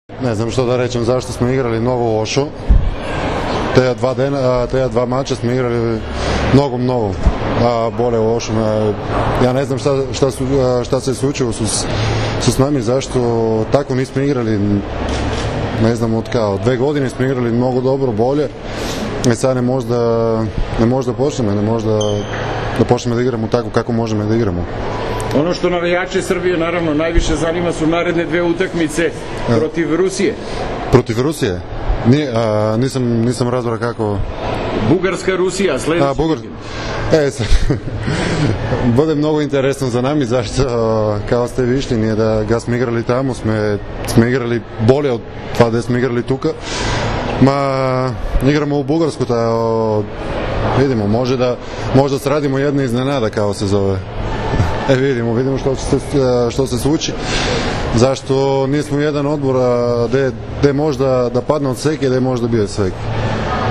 IZJAVA CVETANA SOKOLOVA